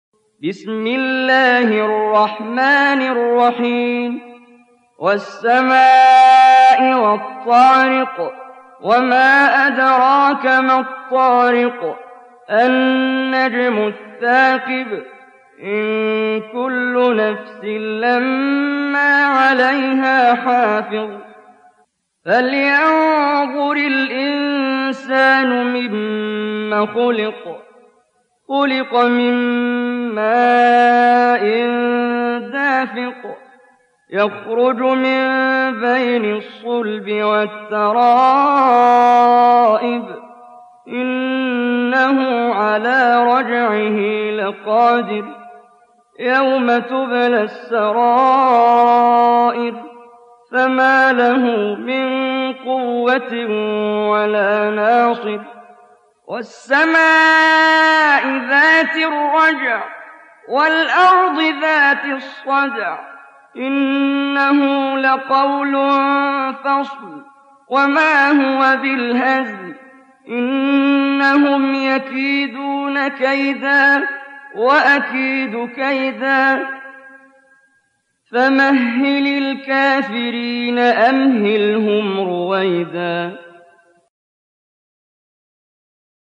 Audio Quran Tarteel Recitation
Surah Sequence تتابع السورة Download Surah حمّل السورة Reciting Murattalah Audio for 86. Surah At-T�riq سورة الطارق N.B *Surah Includes Al-Basmalah Reciters Sequents تتابع التلاوات Reciters Repeats تكرار التلاوات